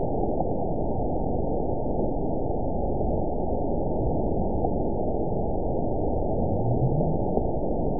event 922795 date 04/06/25 time 00:54:53 GMT (2 months, 1 week ago) score 9.60 location TSS-AB02 detected by nrw target species NRW annotations +NRW Spectrogram: Frequency (kHz) vs. Time (s) audio not available .wav